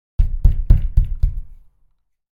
Deep-knocking-on-wooden-door-sound-effect.mp3